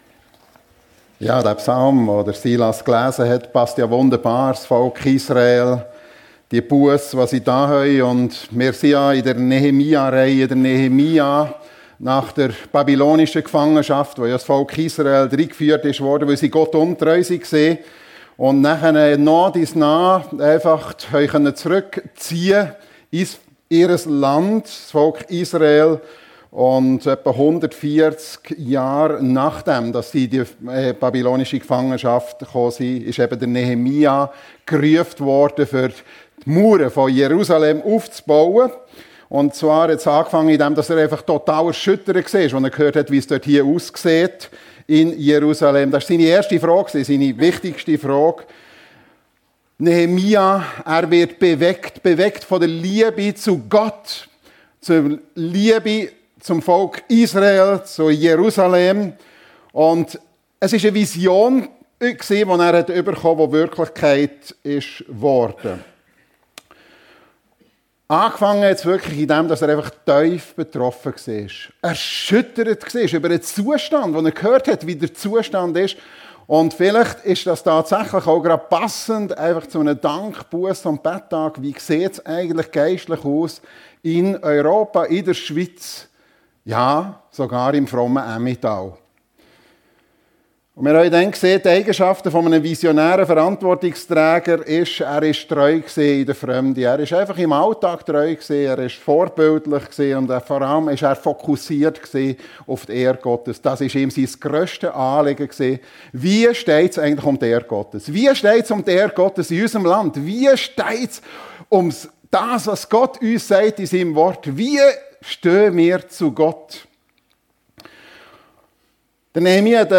Nehemia - Heftiger Gegenwind (Teil 3) ~ FEG Sumiswald - Predigten Podcast